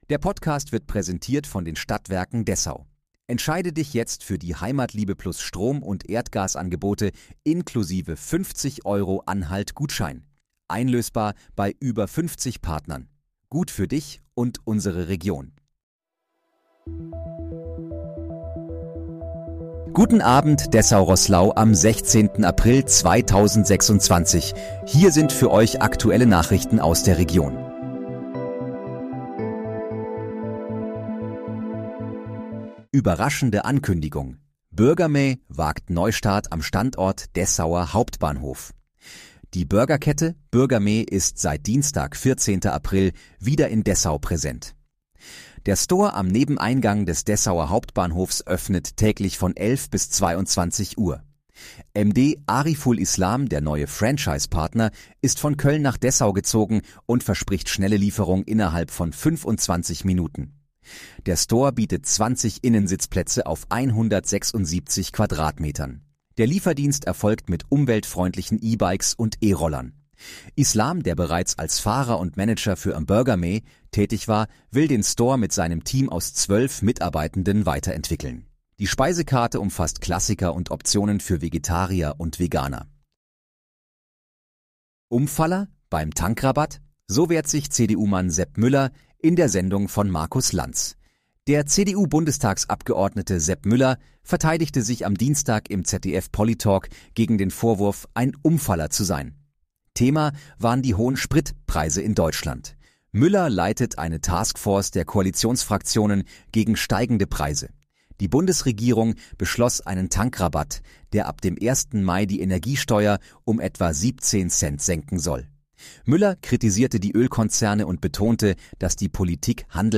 Guten Abend, Dessau-Roßlau: Aktuelle Nachrichten vom 16.04.2026, erstellt mit KI-Unterstützung